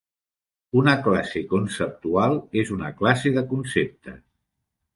Pronounced as (IPA) [kun.səp.tuˈal]